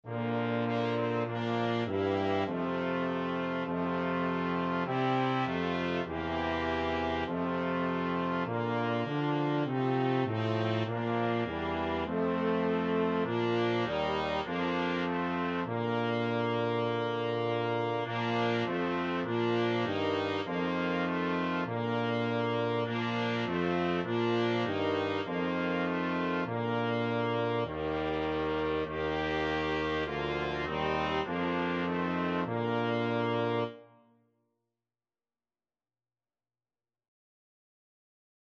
Free Sheet music for Brass Quintet
Trumpet 1Trumpet 2French HornTromboneTuba
Bb major (Sounding Pitch) (View more Bb major Music for Brass Quintet )
4/4 (View more 4/4 Music)
Classical (View more Classical Brass Quintet Music)
bohemian_christmas_BRQN.mp3